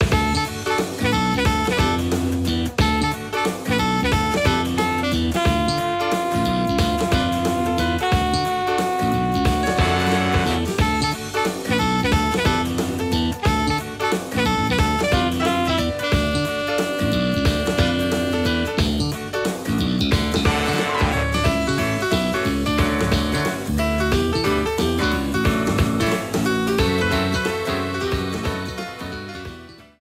Shortened, applied fade-out and converted to oga